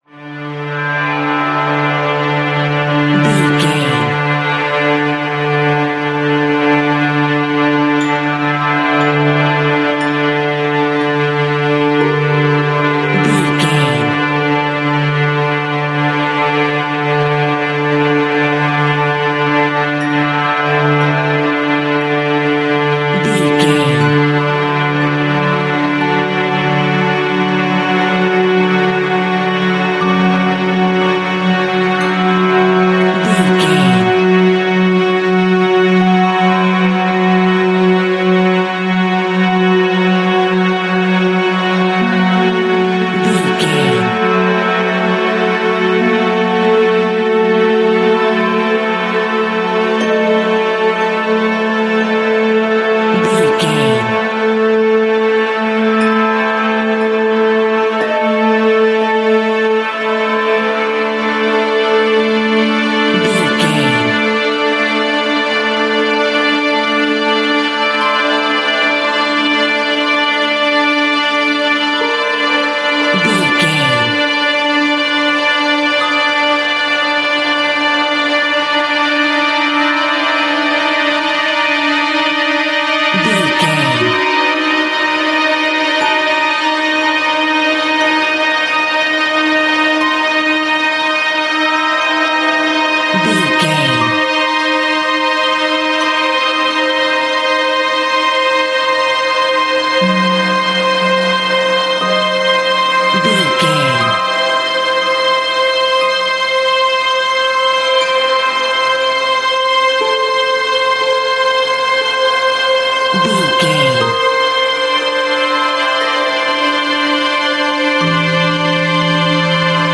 Aeolian/Minor
D
tension
ominous
dark
suspense
eerie
strings
synth
ambience
pads